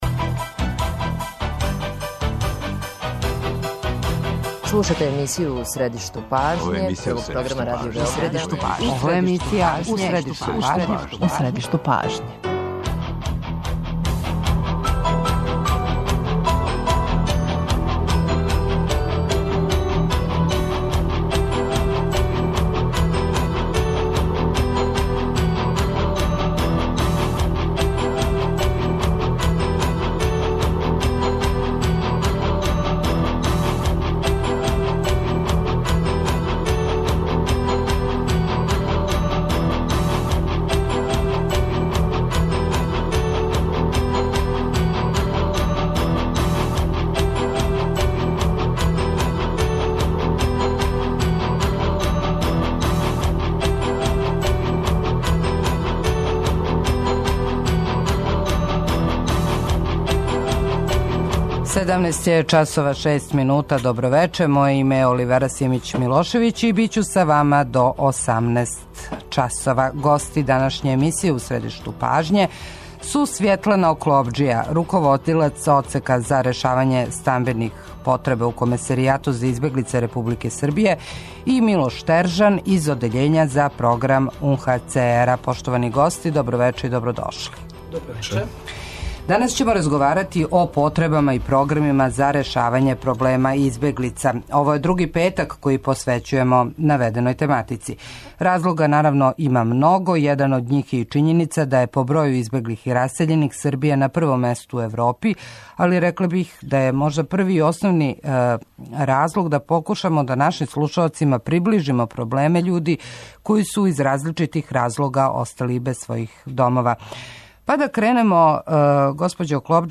Управо зато, Радио Београд 1, у сарадњи са Комесеријатом за избеглице, Међународном организацијом за миграције и Европском унијом, емитује серију разговора о тој тематици.